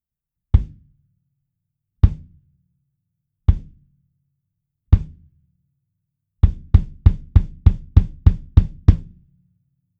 EQ等は一切していません。
バスドラム　OUT
25ドラムキックアウト.wav